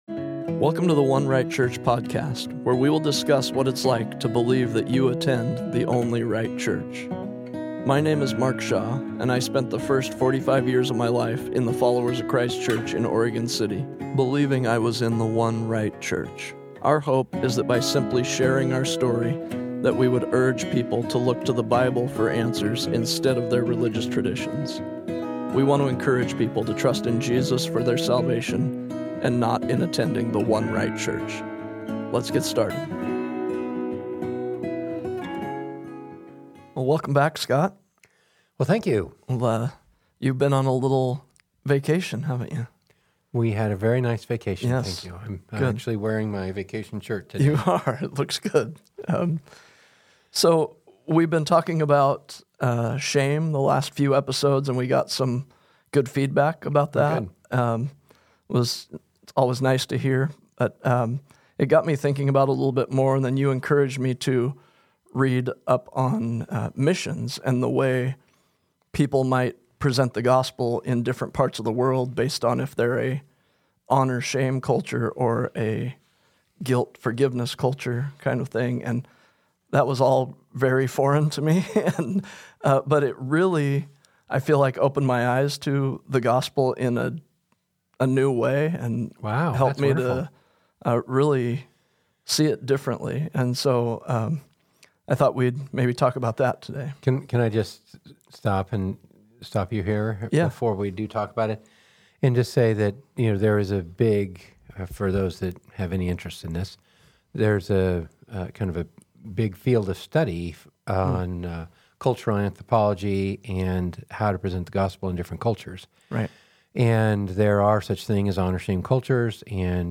We hope this conversation about shame and the Prodigal Son helps you understand the way shame works.